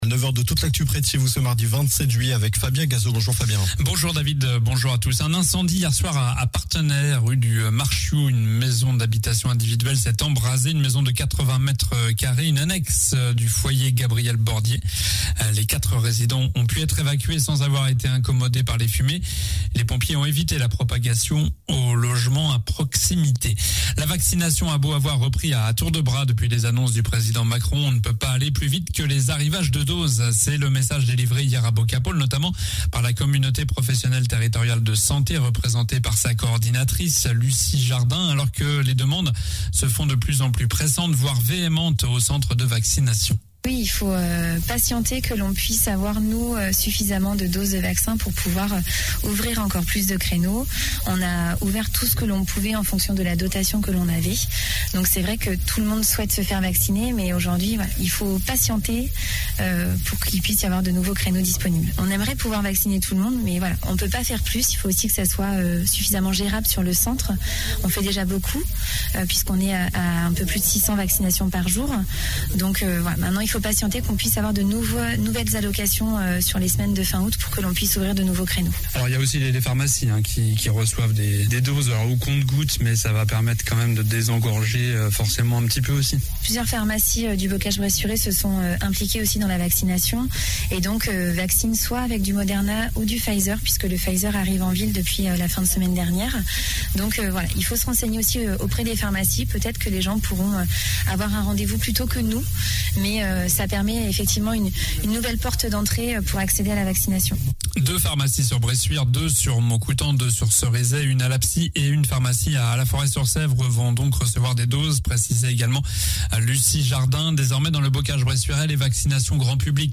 Journal du mardi 27 juillet (matin)